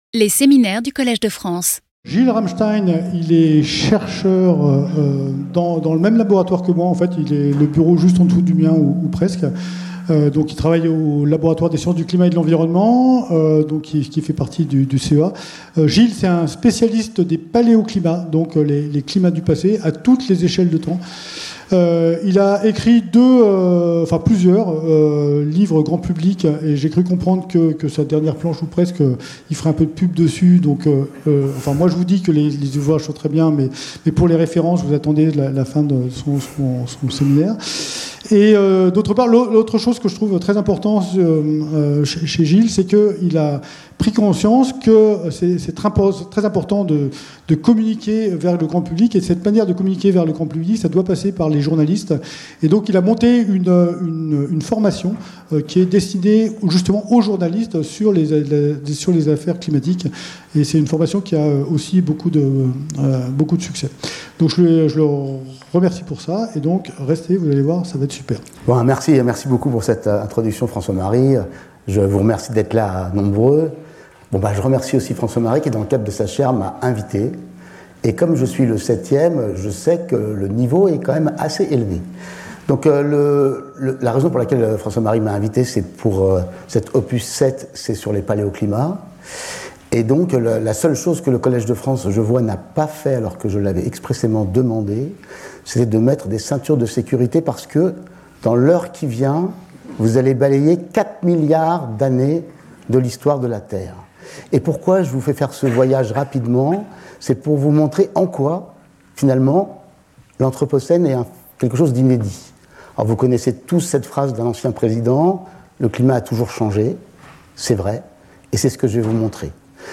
Skip youtube video player Listen to audio Download audio Audio recording Abstract This seminar will illustrate how modeling the Earth system helps us to understand the climatic changes our planet has undergone over its long history.